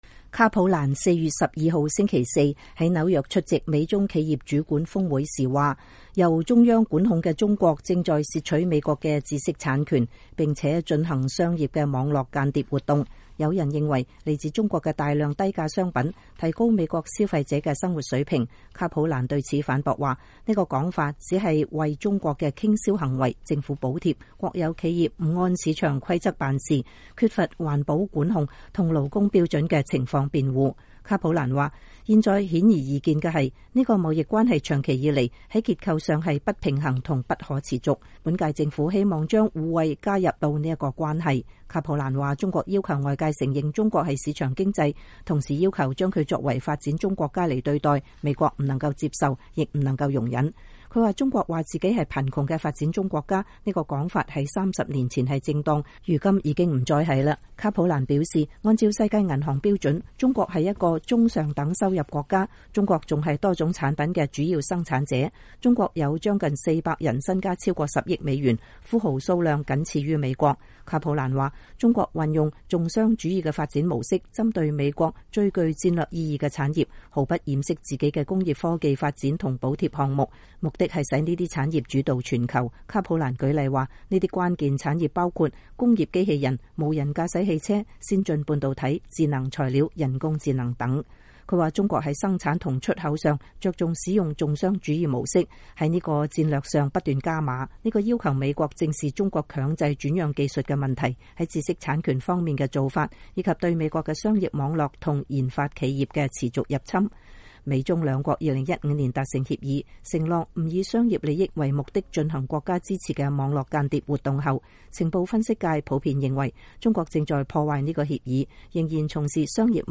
美國商務部國際貿易事務次長吉爾伯特·卡普蘭4月13日在紐約講話
卡普蘭星期四在紐約出席美中企業主管峰會時說：“由中央管控的中國正在竊取美國的知識產權，並且在進行商業的網絡間諜活動”。